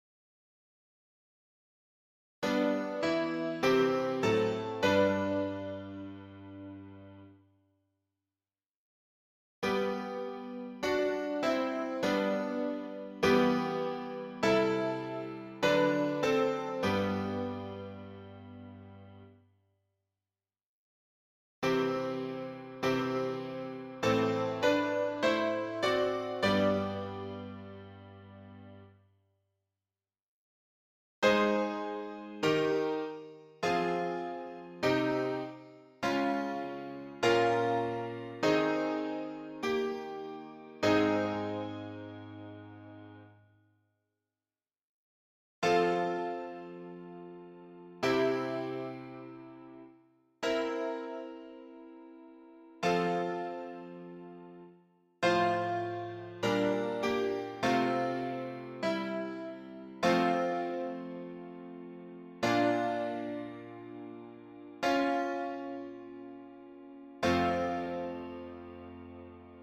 示唱